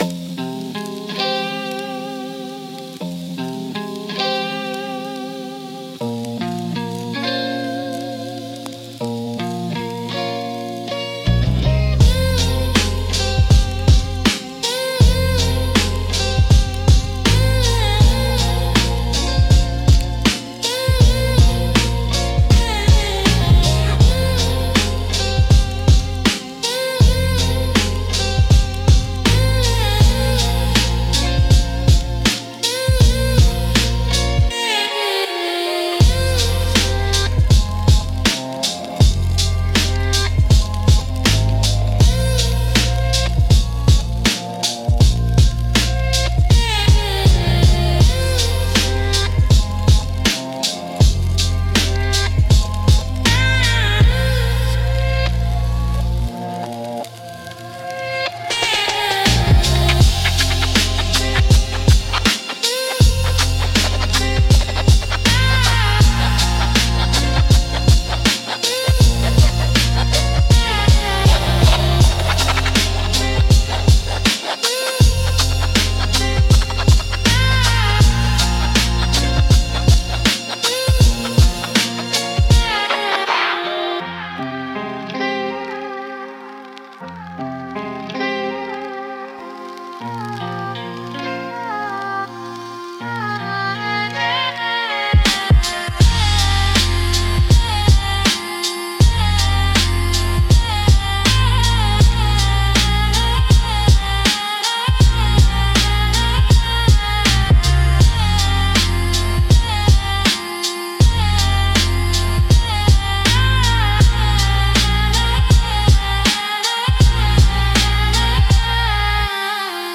Instrumentals - Wobble Through the Void